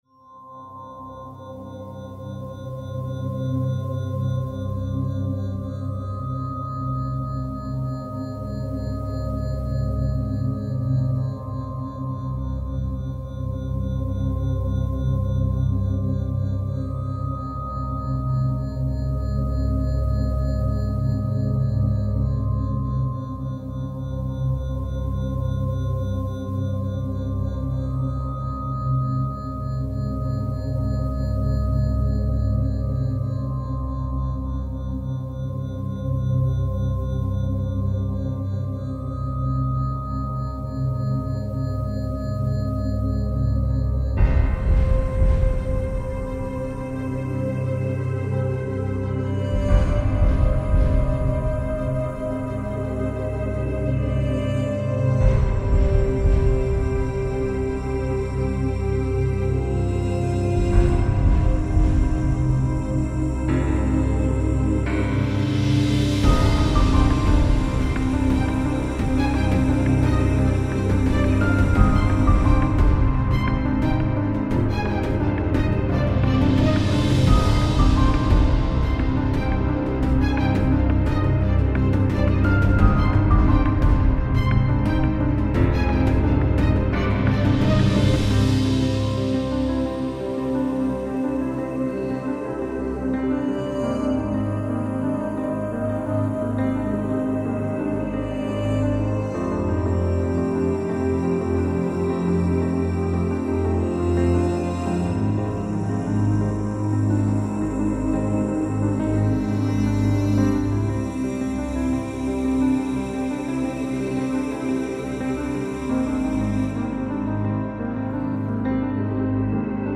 Largo [40-50] suspense - nappes - - -